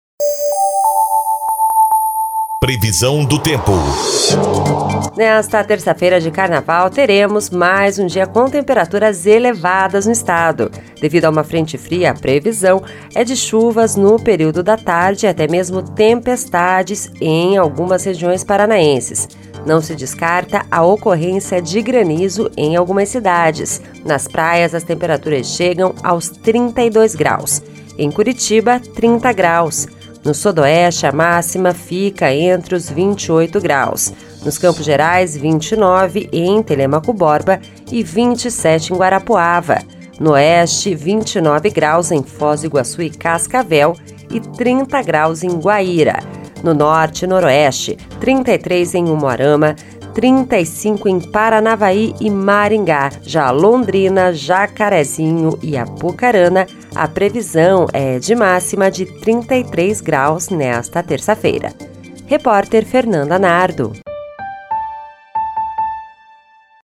Previsão do Tempo (01/03)